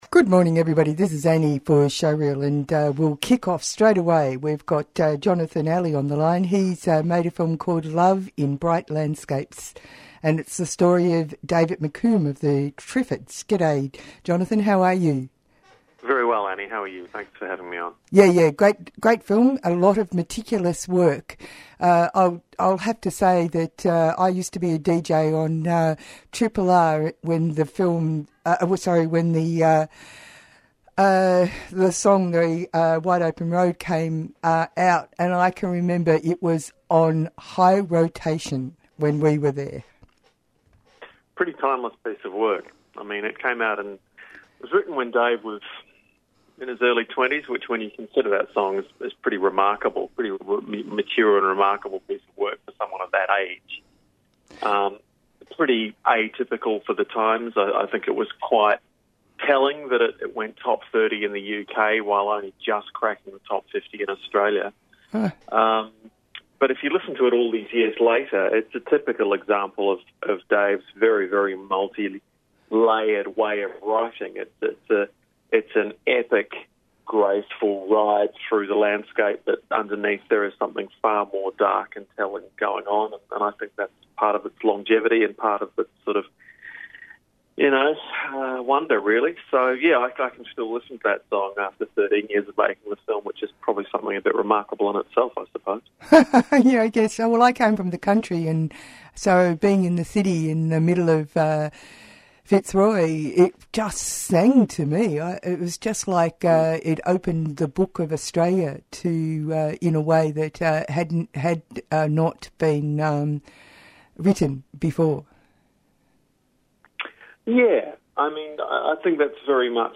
Tweet Showreel Thursday 11:00am to 11:30am Your half hour of local film news, conversations with film makers and explorations into how they bring their ideas to life on screen.